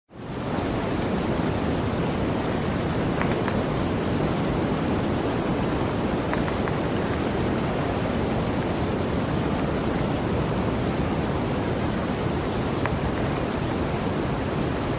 Free Nature sound effect: Snowstorm.
Snowstorm
# snow # blizzard # winter About this sound Snowstorm is a free nature sound effect available for download in MP3 format.
290_snowstorm.mp3